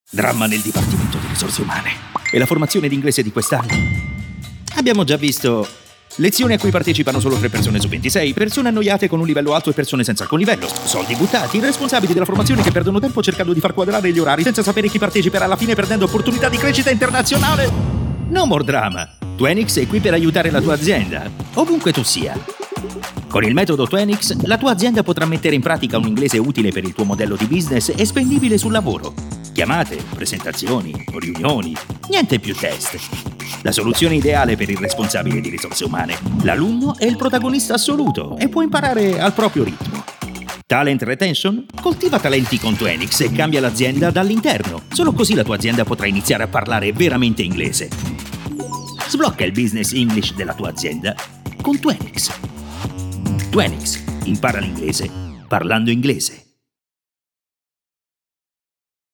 and i'm a Professional Italian Voiceover with baritonal and adaptable voice for each project
Sprechprobe: Werbung (Muttersprache):
I have a Home Studio and all the knowledge to edit and send professional, quality audio.